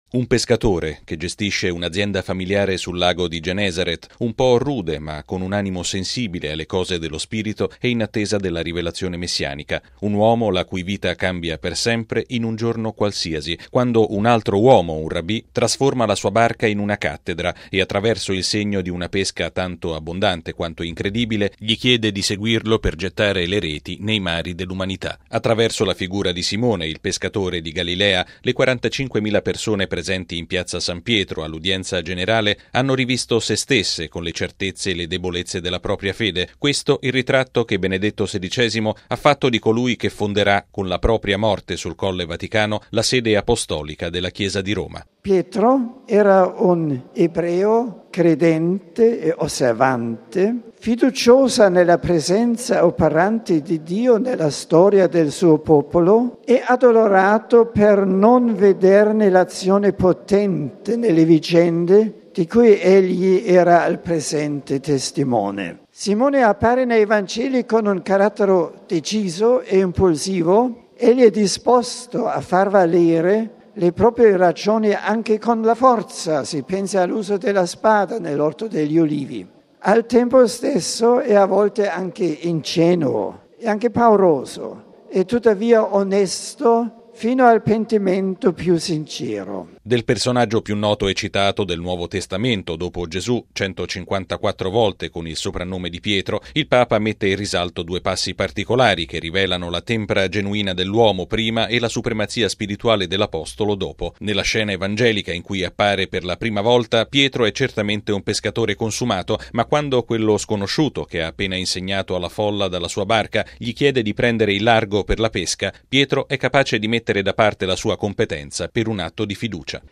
Benedetto XVI all'udienza generale: no al cristianesimo "fai da te": l'uomo segua Cristo con il coraggio e l'umiltà di Pietro
(17 maggio 2006 - RV) La figura di Pietro, il primo degli Apostoli di Gesù, ha caratterizzato la catechesi di Benedetto XVI all’udienza generale di stamattina in Piazza San Pietro. Il Papa ha tratteggiato la parabola umana e spirituale del pescatore di Galilea, che scelse di affidarsi a Cristo e divenne protagonista di una “grande avventura”.